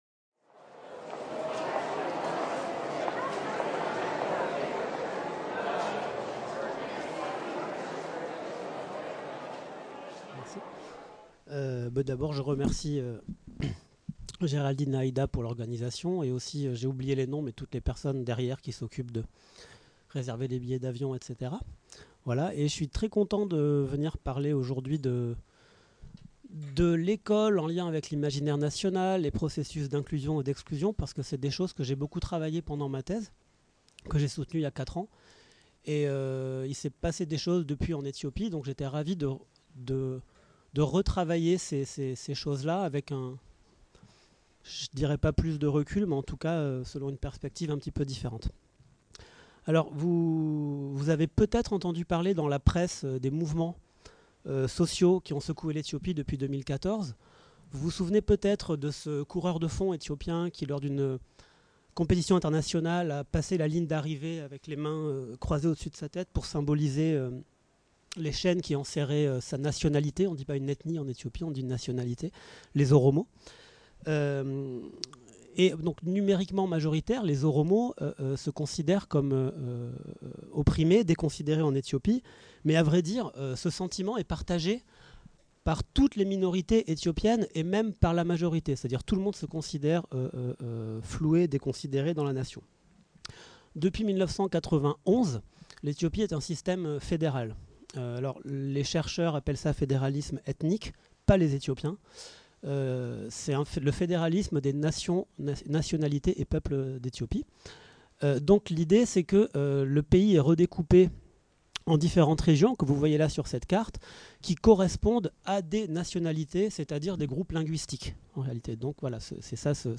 Journée d’études organisée par l’Urmis, Université Côte d’Azur, IRD, le jeudi 13 décembre 2018 à Nice.